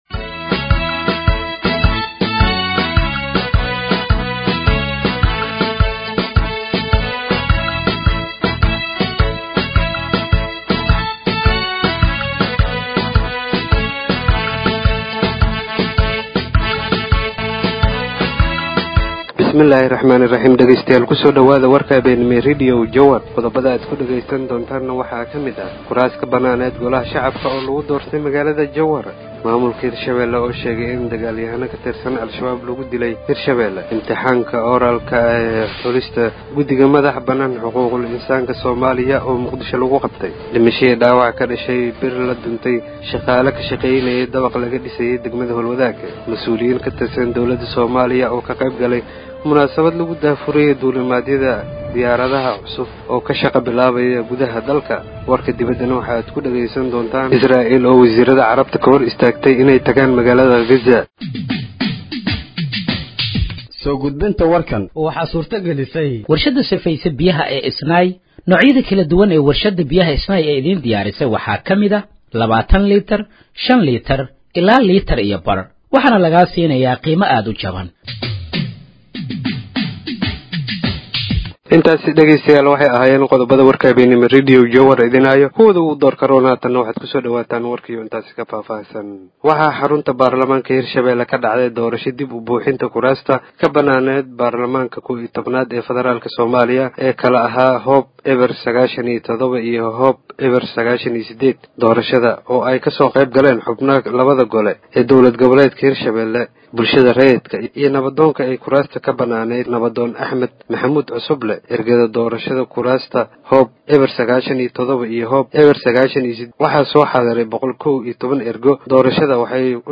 Dhageeyso Warka Habeenimo ee Radiojowhar 31/05/2025